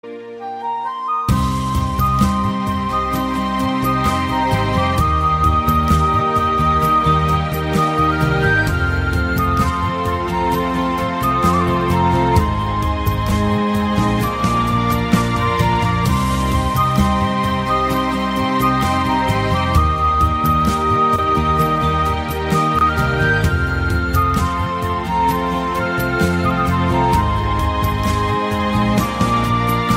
Mp3 Bollywood Category